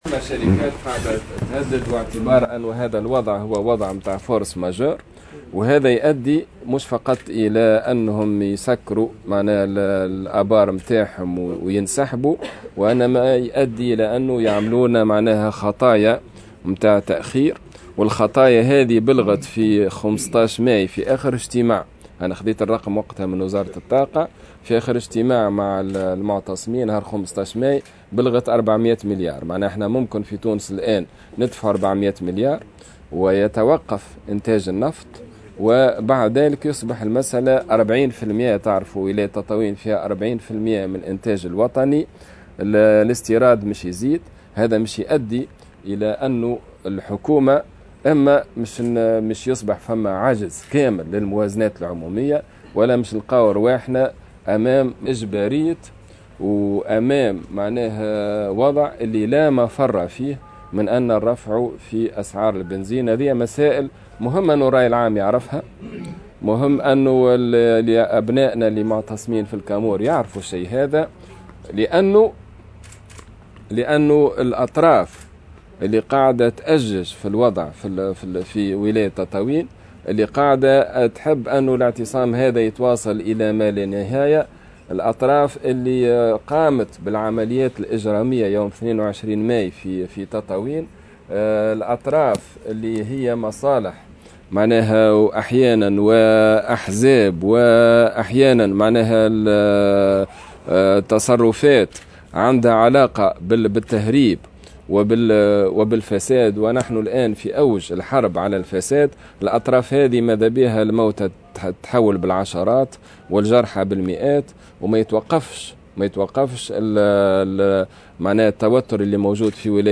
وأكد الحمامي، في تصريح لمراسل الجوهرة أف أم، إثر إجتماع اللجنة العليا لمتابعة القرارات الخاصة بملف تطاوين، والتي غاب عنها ممثلو تنسيقية اعتصام الكامور، أن توقف إنتاج النفط في تطاوين التي توفر 40 % من إنتاج البلاد، سيؤدي إلى عجز في الموازنة وهو ما سيدفع الحكومة إلى الترفيع في أسعار المحروقات.